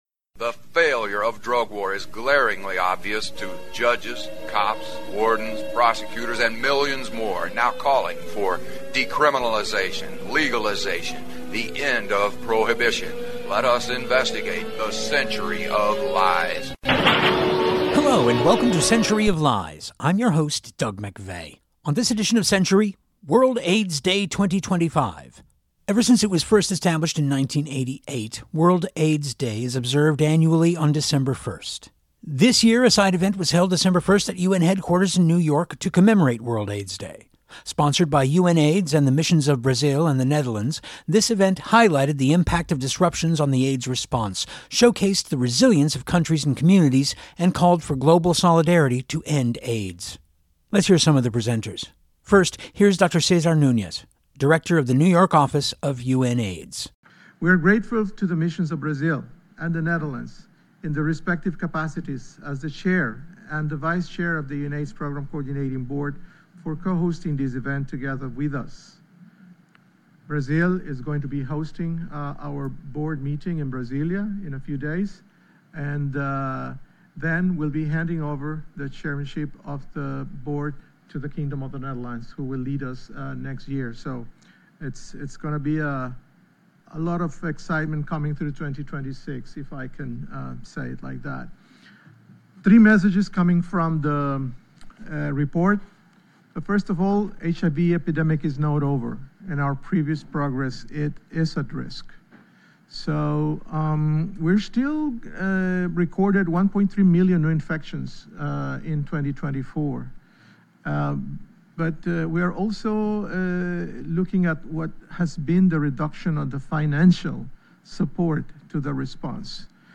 This year, a side event was held December 1 at UN headquarters in New York to commemorate World AIDS Day. Sponsored by UNAIDS and the Missions of Brazil and the Netherlands, this event highlighted the impact of disruptions on the AIDS response, showcased the resilience of countries and communities, and called for global solidarity to end AIDS.